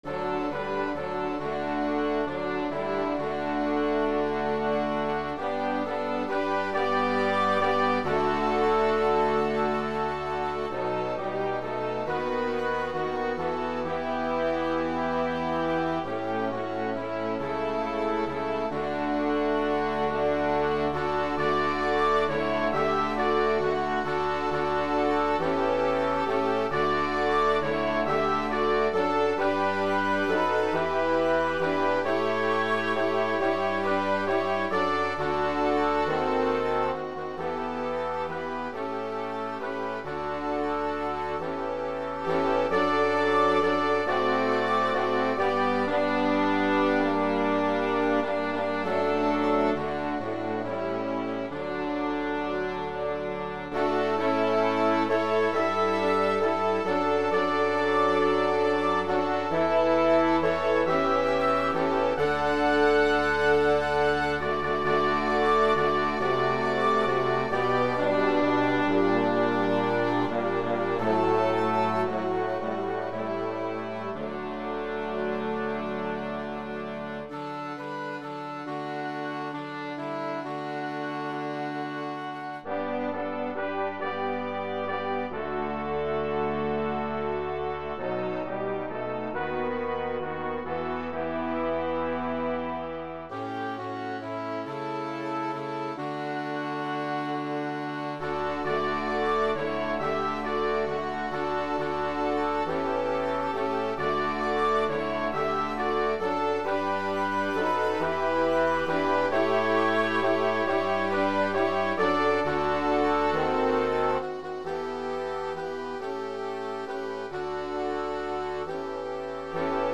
This is a hymn I arranged for band with the FINALE music program.
WAV file and then I used my MIDI TO WAV program to convert it to an MP3.